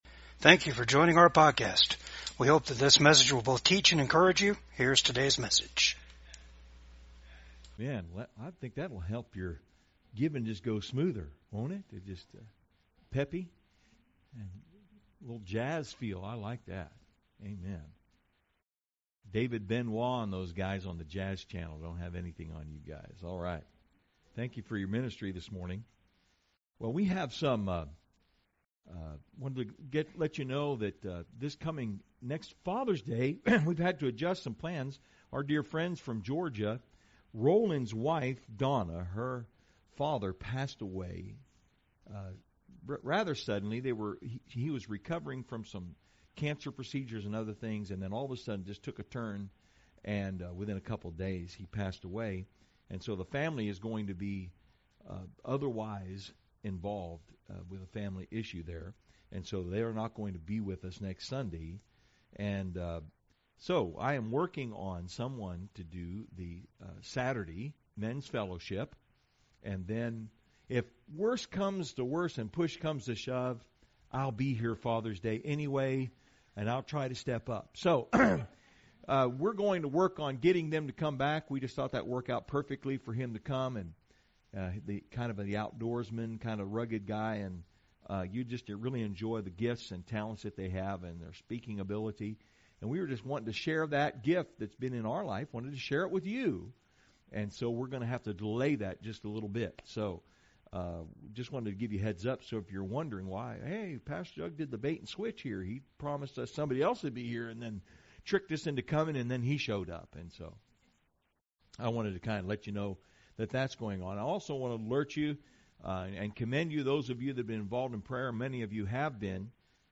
Philippians 3:1-14 Service Type: VCAG SUNDAY SERVICE THE ULTIMATE FULFILLMENT OF LIFE IS BEING CONNECTED TO THE SPIRIT OF GOD.